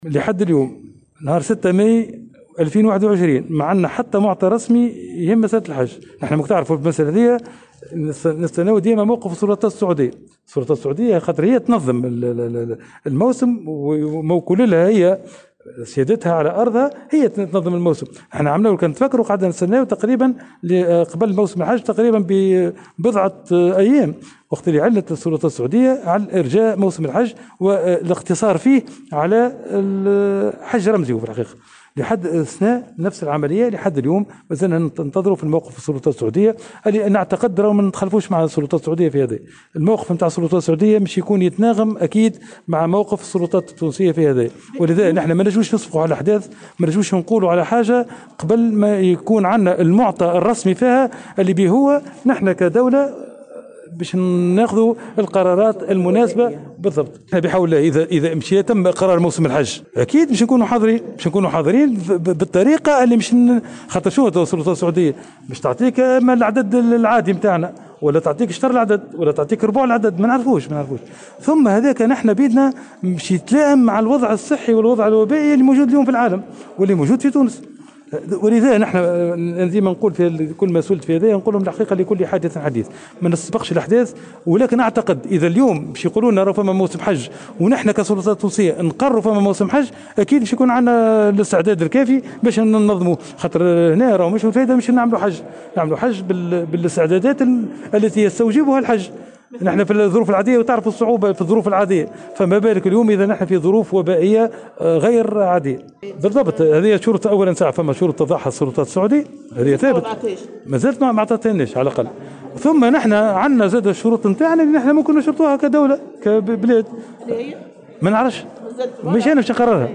وبين وزير الشؤون الدينية لدى اشرافه على حفل تكريم الناجحين في المسابقة الوطنية لحفظ القران الكريم في دورتها 52، أن الوزارة بانتظار موقف السلطات السعودية مثل السنة الفارطة، حين أعلنت عن ارجاء الحج والاقتصار على حج رمزي، مضيفا أن الموقف السعودي سيكون في تناغم مع موقف السلطات التونسية والملائم للوضع الصحي في العالم.